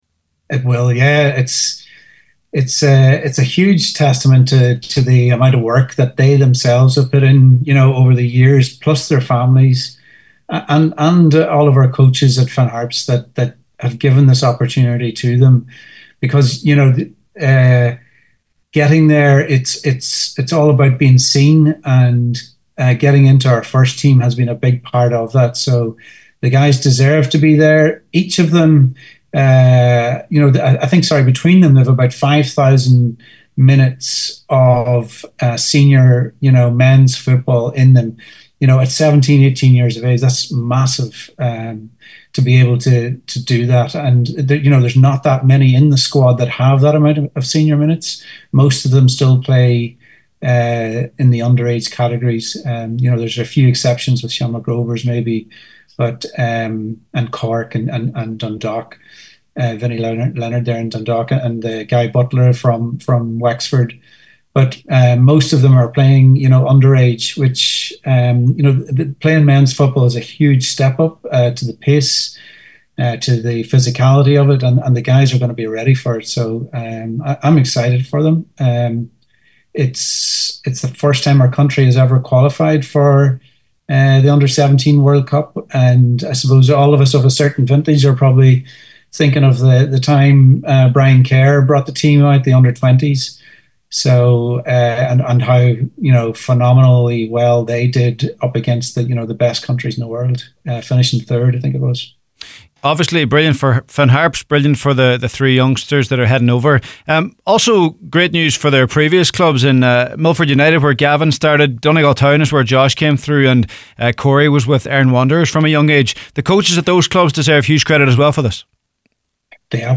Speaking on The Score programme